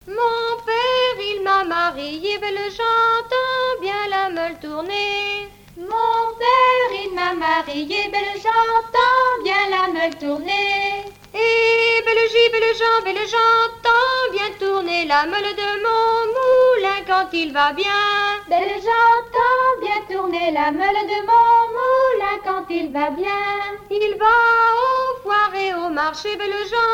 chanson de noce
Le répertoire du groupe folklorique Torr'Niquett' pour la scène
Pièce musicale inédite